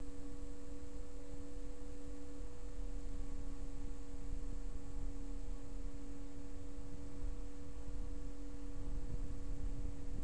Maximale Umdrehung bei 2400 U/min
enermax_warp_120_max.wav